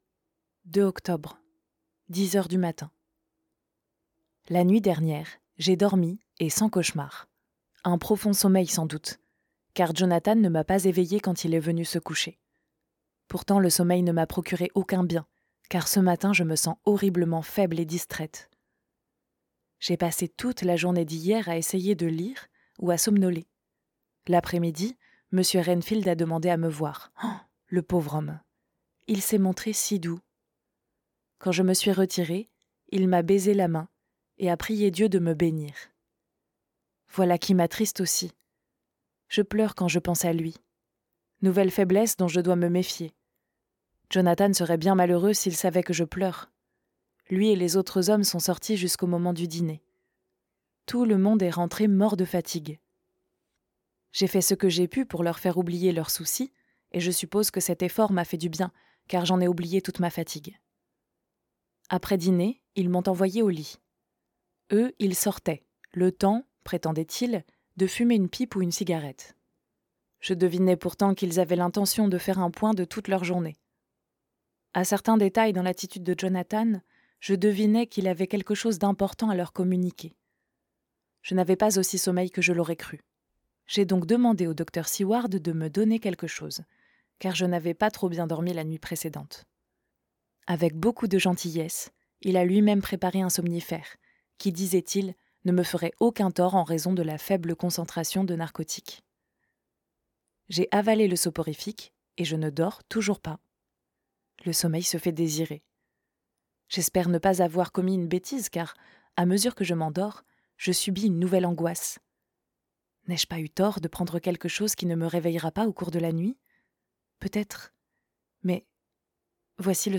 Extrait de Dracula - Livre Audio
Voix off Narratrice